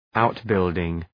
Προφορά
{‘aʋt,bıldıŋ}